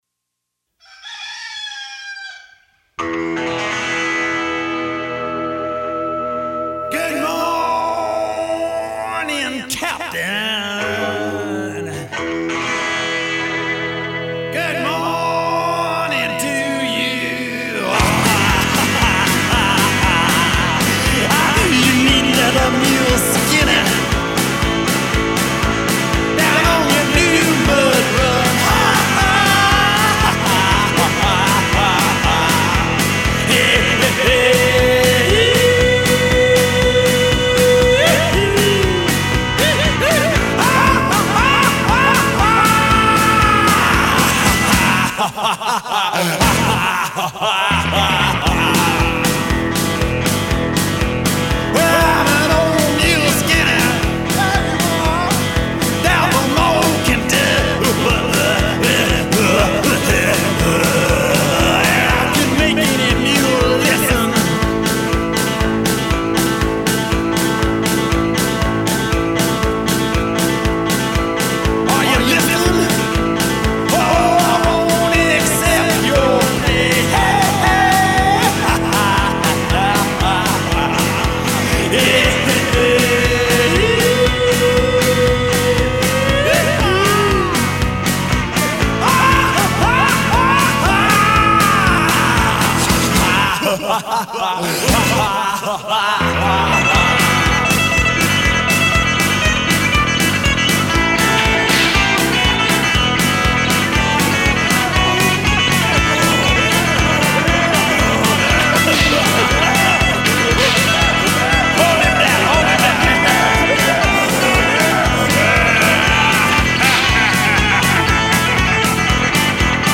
The twang’s the thang, baby!